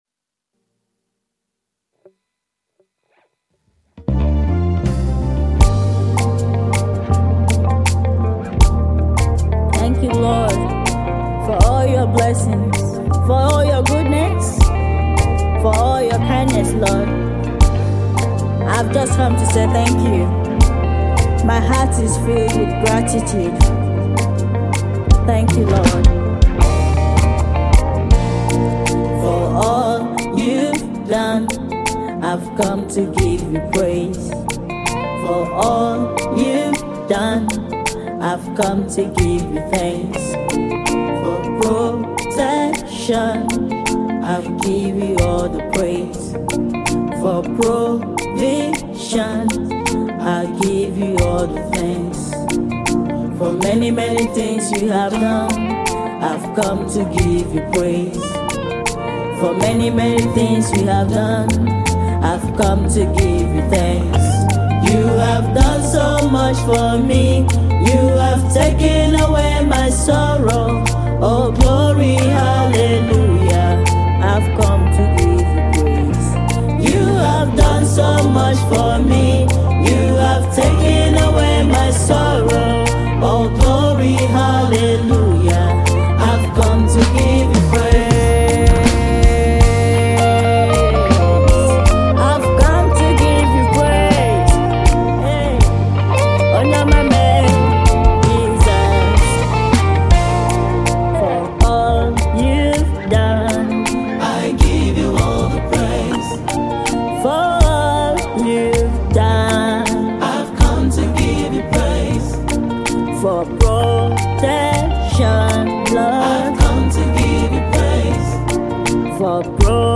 Music
a passionate and heartfelt thanksgiving song.